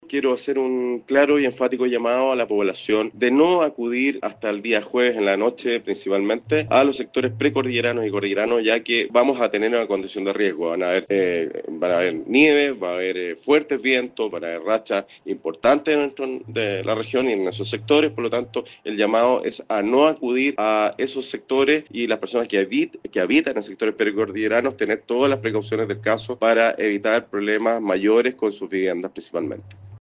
Así lo declaró el director de Senapred en O’Higgins, Marcelo Montecinos, escuchemos: